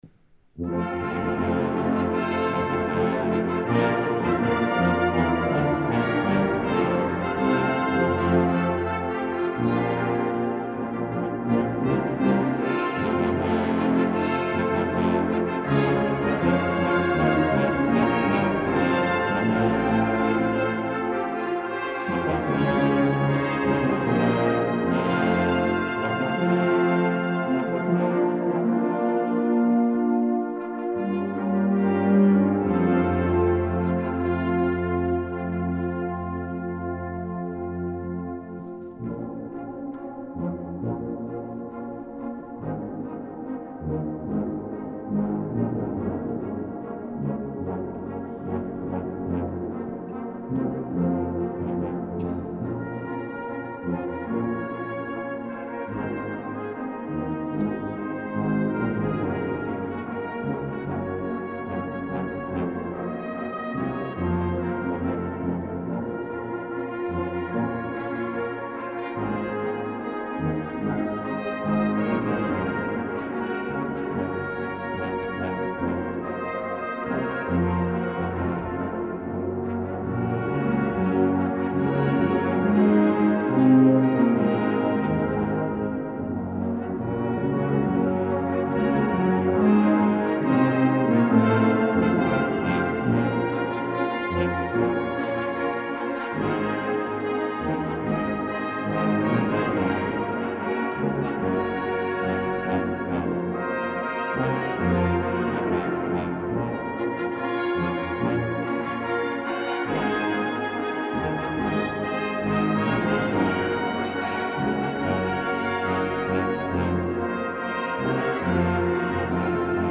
Warm, weit, strahlend – muss man selbst hören
Die Aufnahmen wurden von verschiedenen Ensembles aufgenommen und zur Verfügung gestellt.